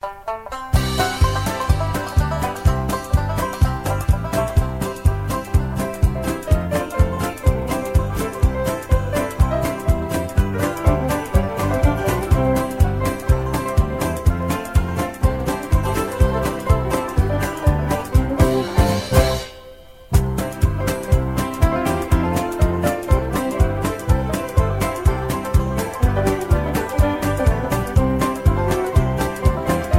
Singing Call (instrumental) + Patter